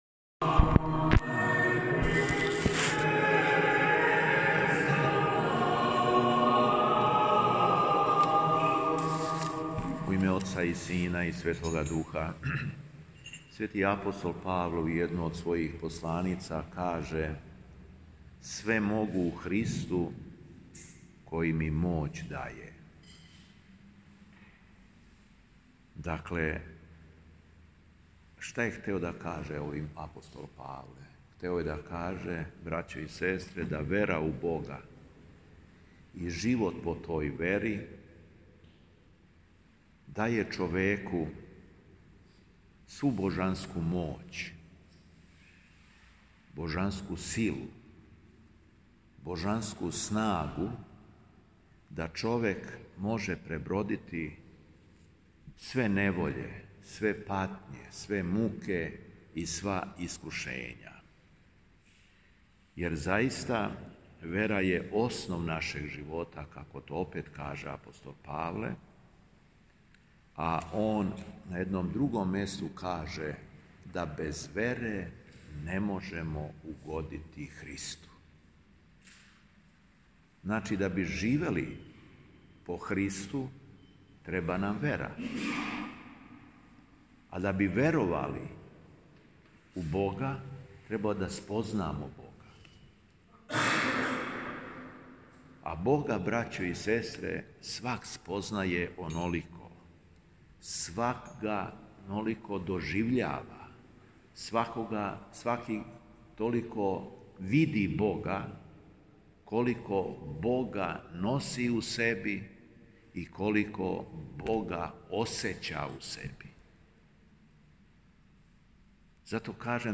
У понедељак седмице блуднога Сина, када се припремамо за Велики и Часни Пост, и када се свакодневно враћамо натраг у загрљај Оцу своме Небескоме, Његово Преосвештенство служио је свету архијерејску литургију у храму Светога Саве на Аеродрому.
Беседа Епископа шумадијског Г. Јована